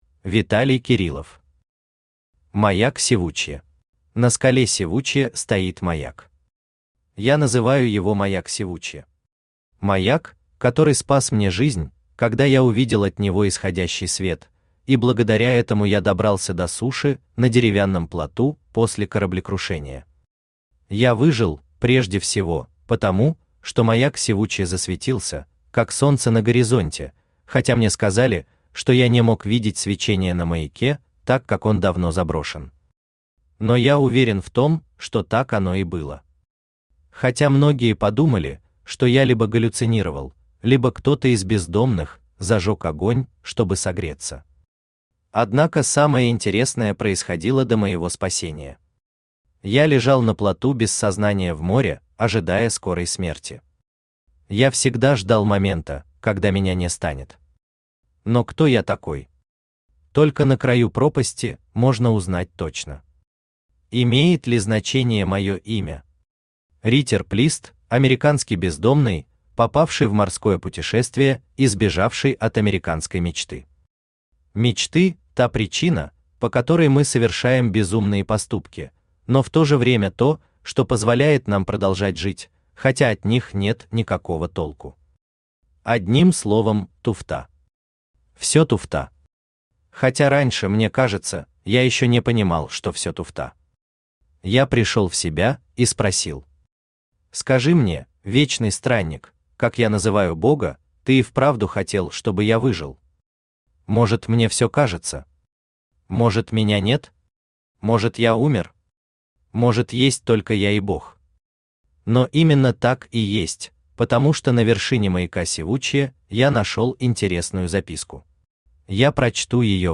Аудиокнига Маяк Сивучья